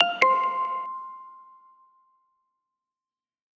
notification sound for notify()
notification.ogg